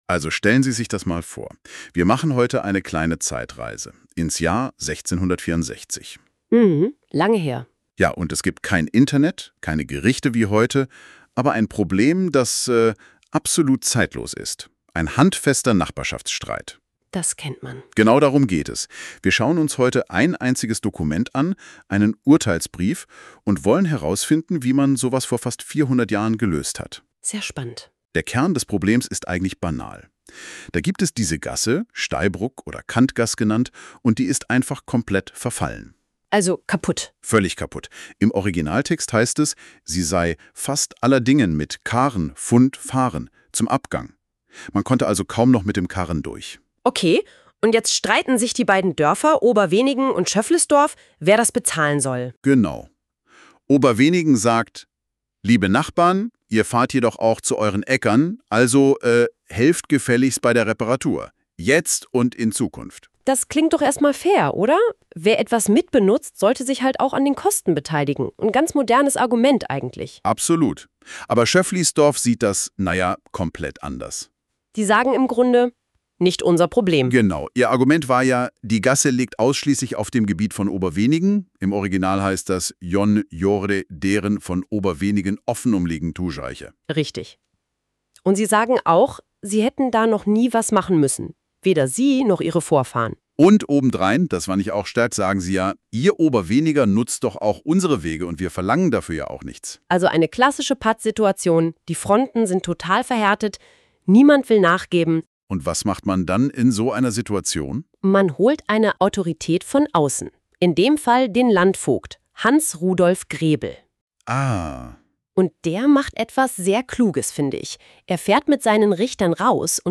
KI-generiertes Gespräch (Audio)